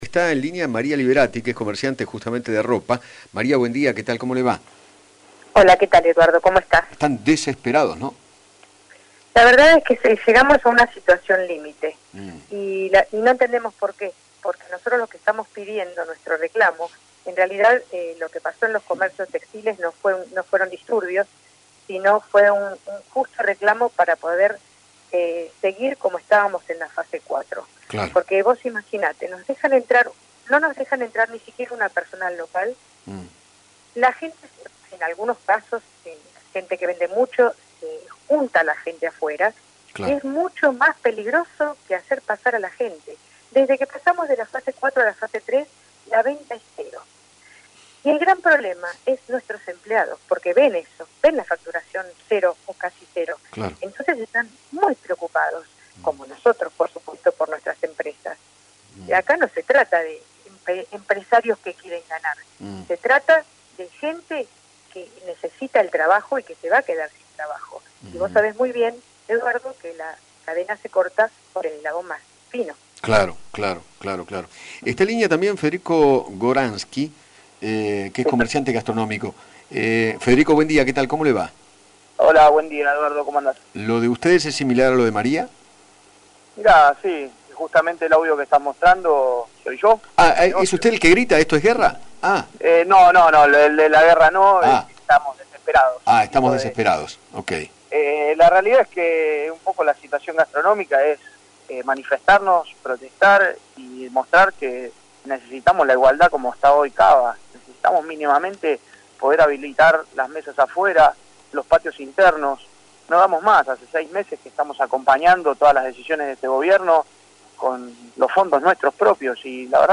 Eduardo Feinmann dialogó con dos comerciantes de diferentes rubros de Mar Del Plata para que cuenten lo que está sucediendo en la Ciudad con las restricciones que les toca repetir debido al retroceso de la Fase 4 a la 3, que se dio hace casi un mes.